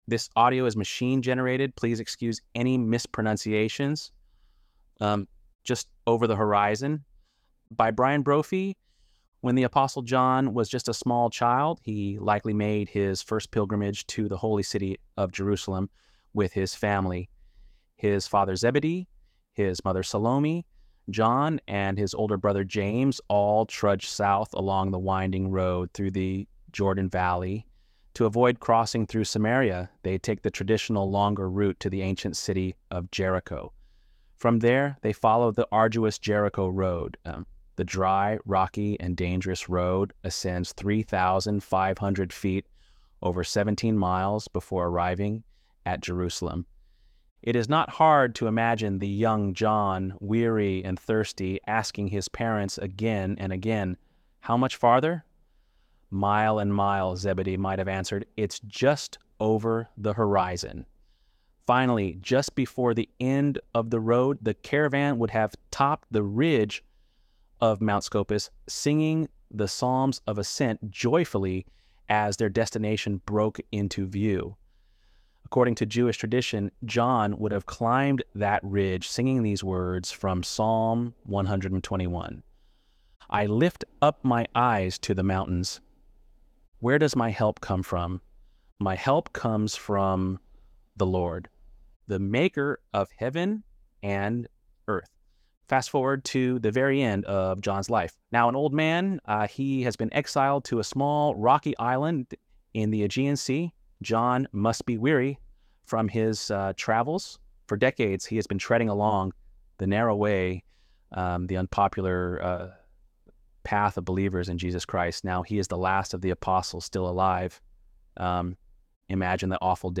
ElevenLabs_7.18_H.mp3